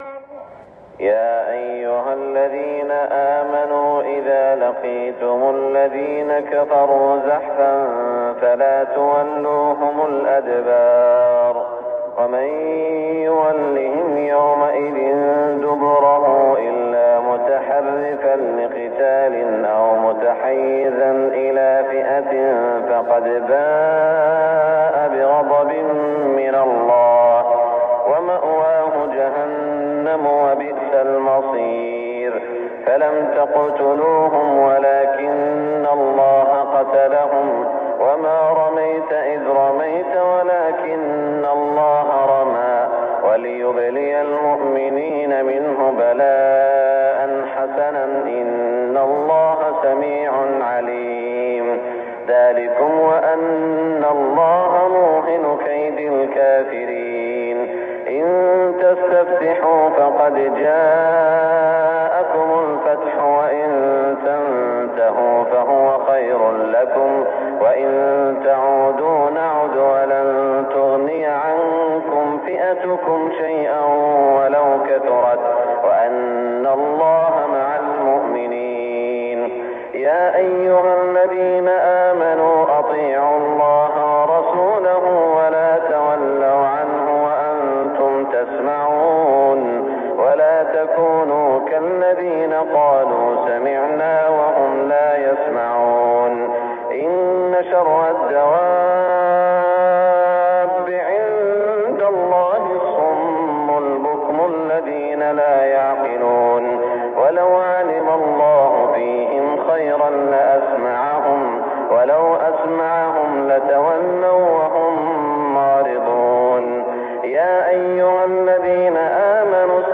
صلاة الفجر 1420 من سورة الأنفال > 1420 🕋 > الفروض - تلاوات الحرمين